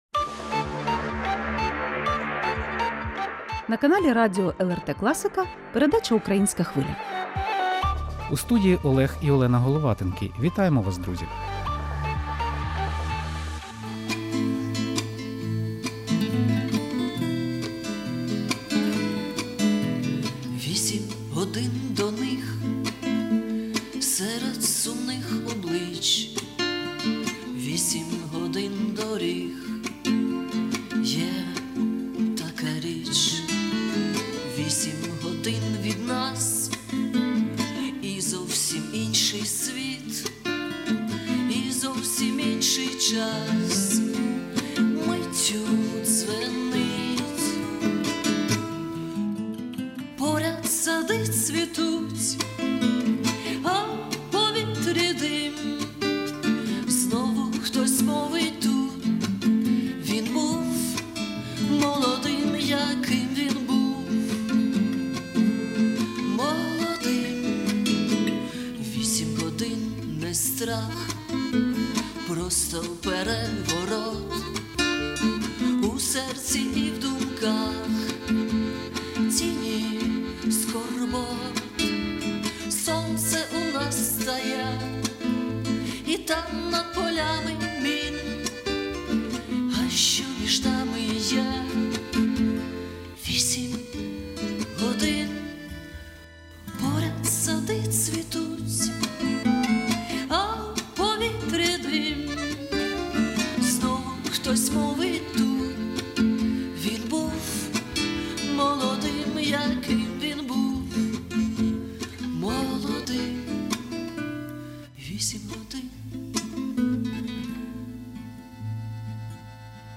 Військові волонтери - традиційно найшанованіші гості студії “Української Хвилі”.